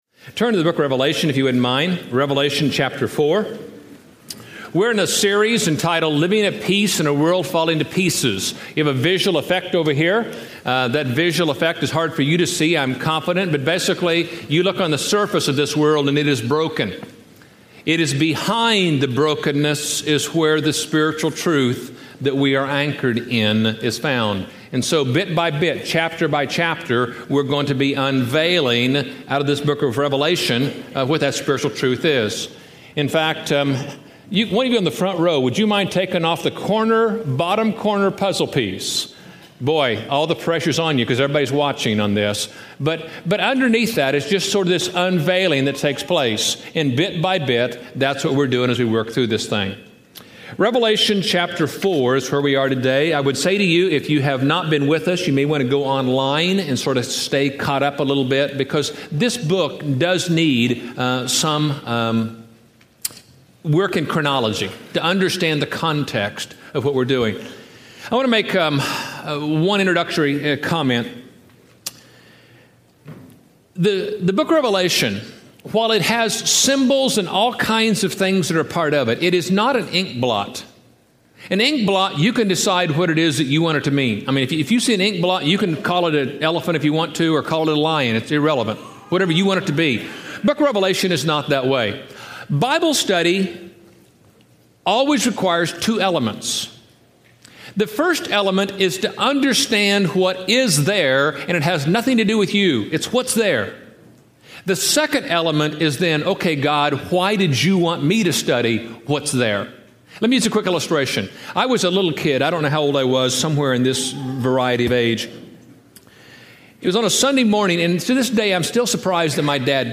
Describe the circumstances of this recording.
Everyone Loves the Song Service Preached at College Heights Christian Church April 29, 2007 Series: Living at Peace in a World Falling to Pieces Scripture: Revelation 4-5 Audio